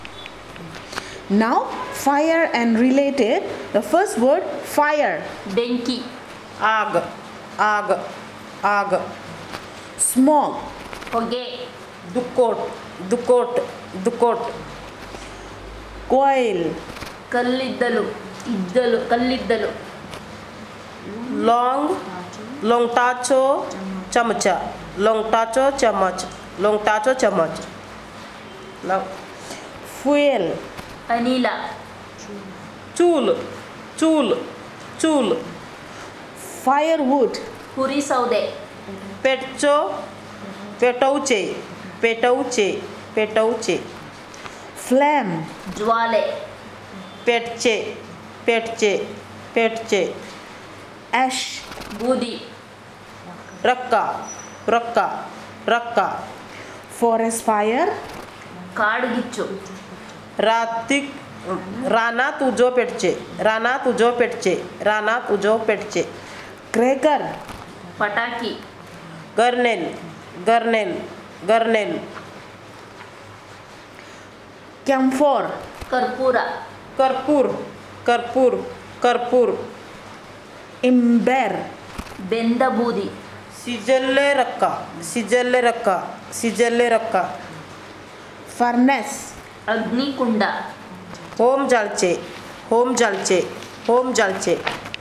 Elicitation of words about fire and related